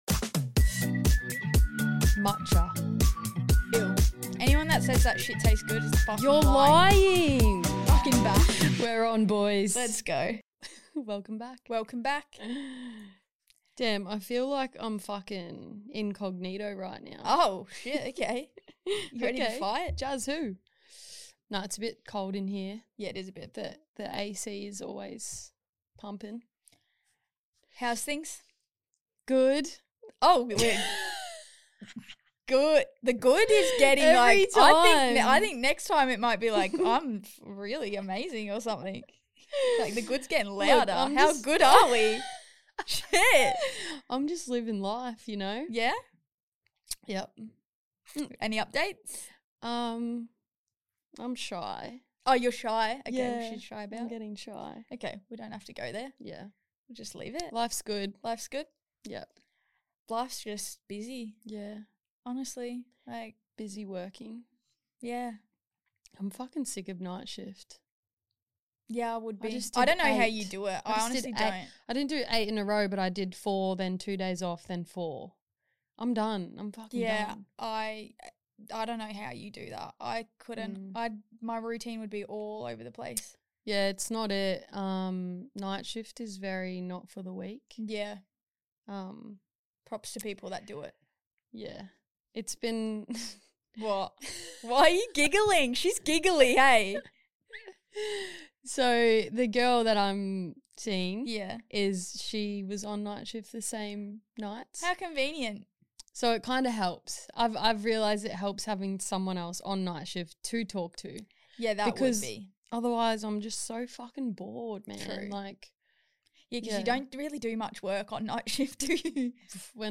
Two masc best friends and their silly little podcast